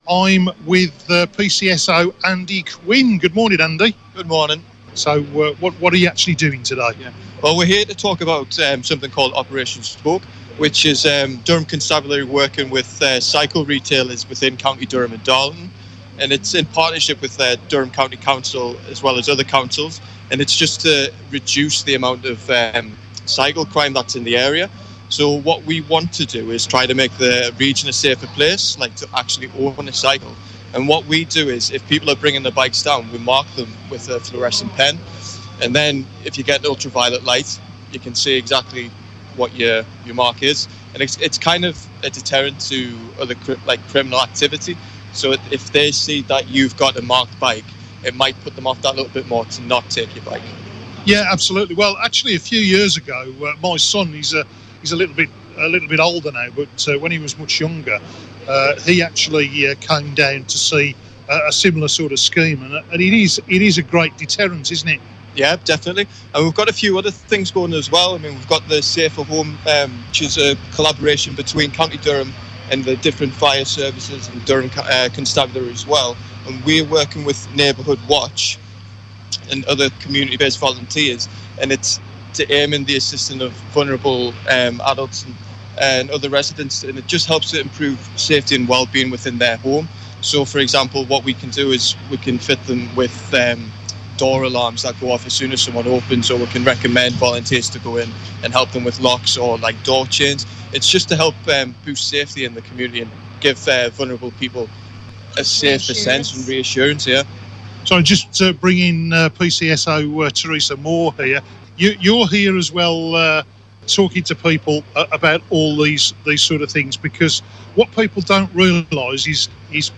The Safer Neighbourhood Unit of Durham Constabulary have been promoting Operation Spoke and home security at Bishop Auckland fire station's open day.